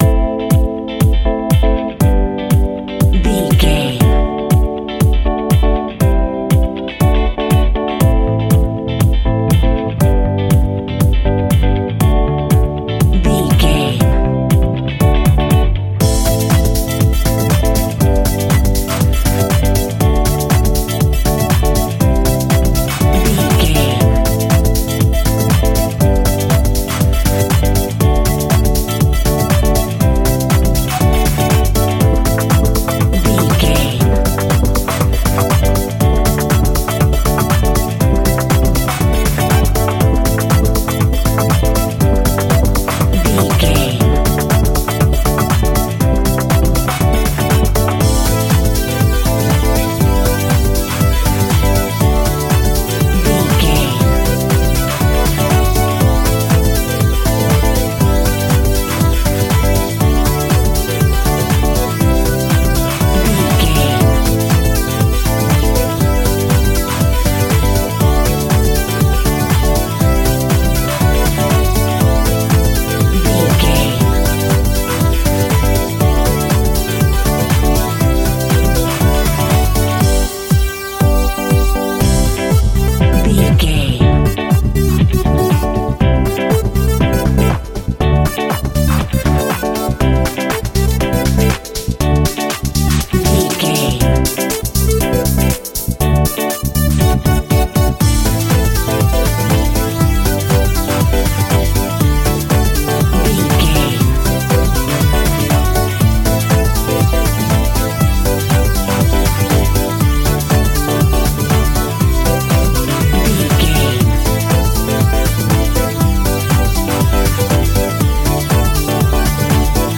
Ionian/Major
aggressive
powerful
fun
groovy
uplifting
futuristic
drums
bass guitar
electric piano
synthesiser
dance
electro
disco
deep house
nu disco
upbeat
funky guitar
clavinet
synth bass
horns